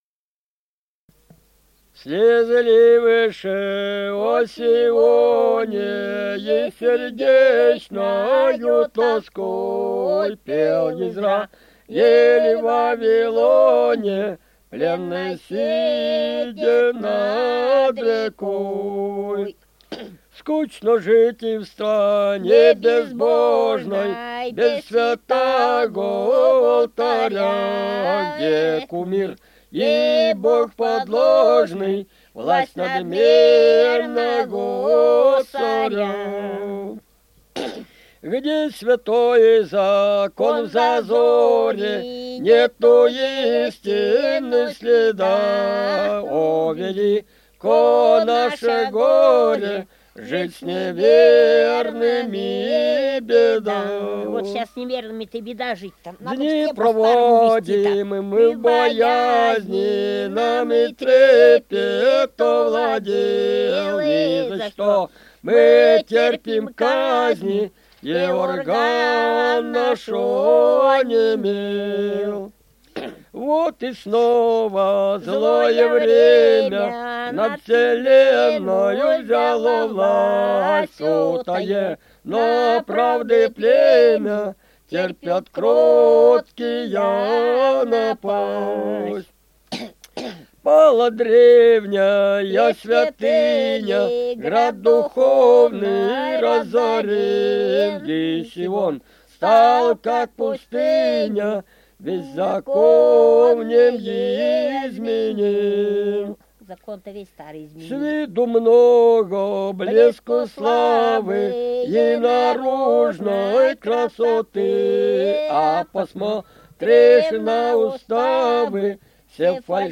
Русские песни Алтайского Беловодья 2 «Слезы ливши о Сионе» (Плач Израиля), духовный стих.
Республика Алтай, Усть-Коксинский район, с. Тихонькая, июнь 1980.